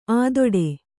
♪ ādoḍe